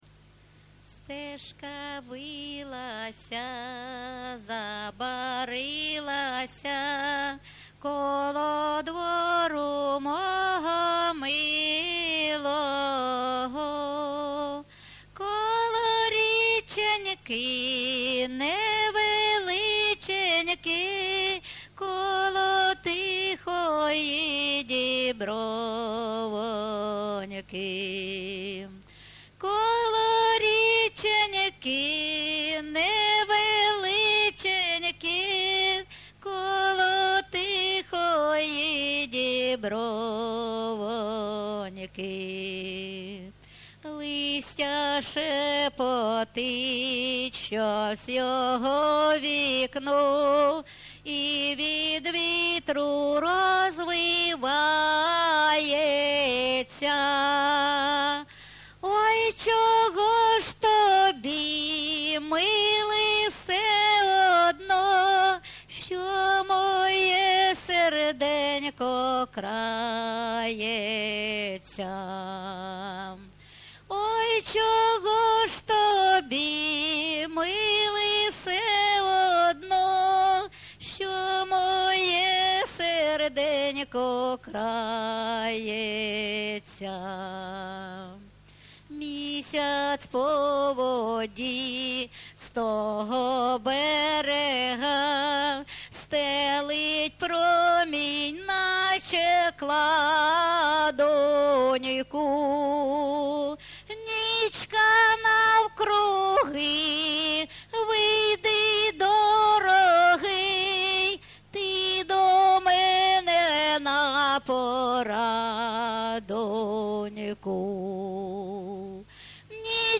ЖанрСучасні пісні та новотвори
Місце записус-ще Новодонецьке, Краматорський район, Донецька обл., Україна, Слобожанщина